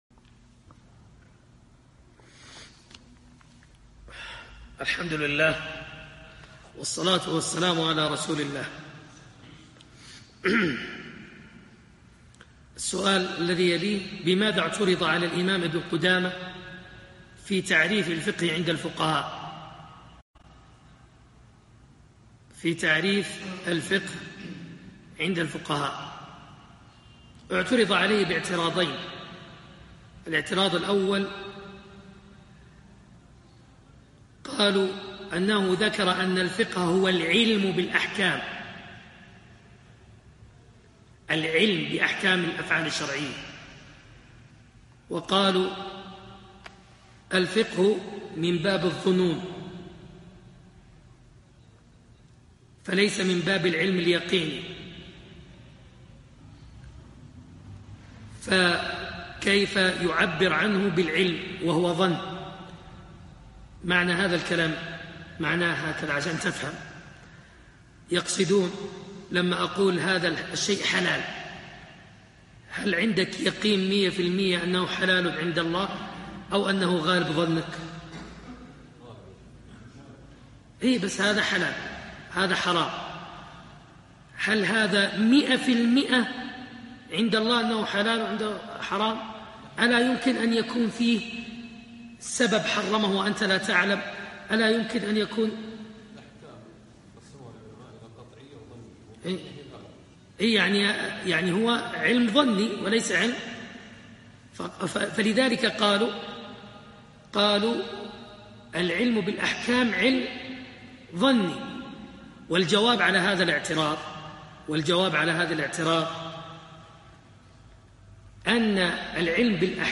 إكمال الدرس الاول في روضة الناظر لابن قدامة رحمه الله